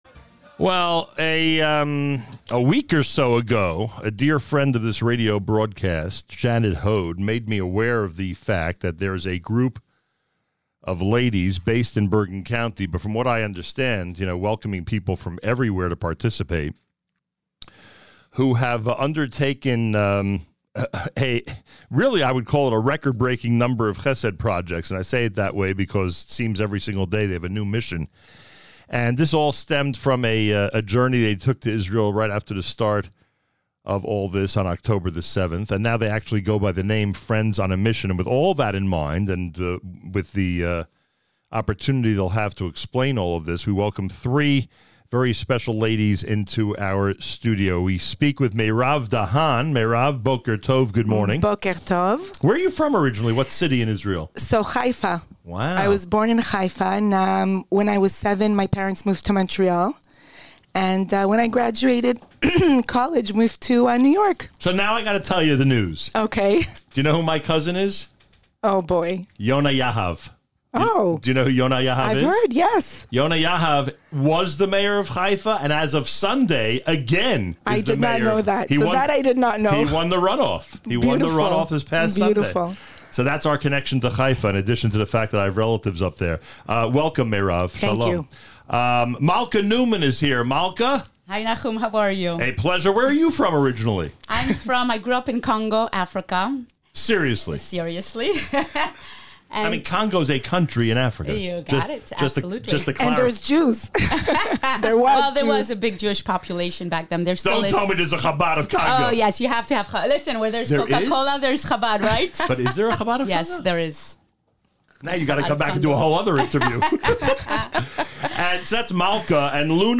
to the studio during this morning’s JM in the AM